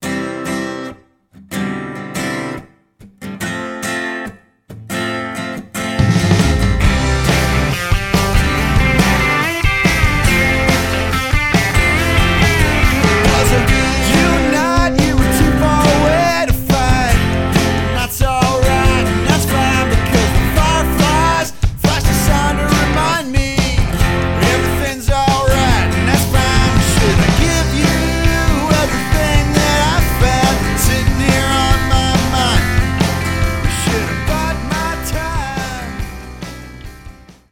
Rock/Country